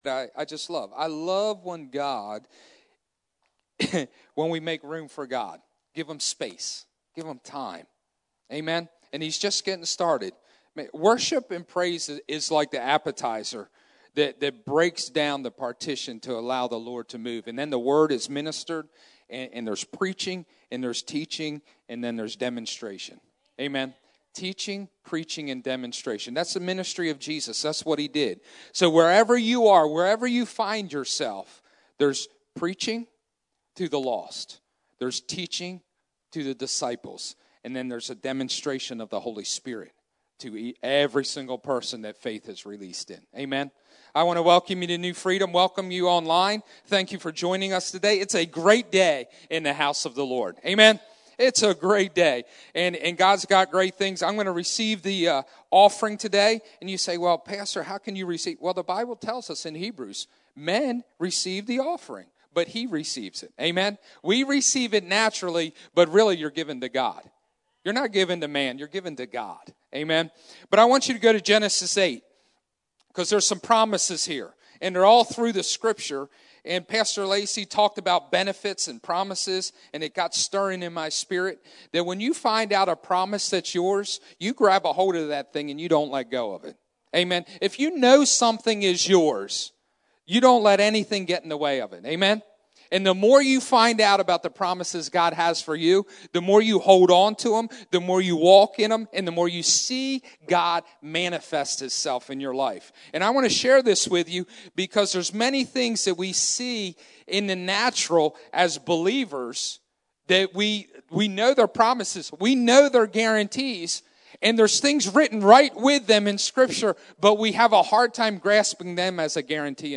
Sermons | New Freedom Church